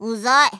Worms speechbanks
Oinutter.wav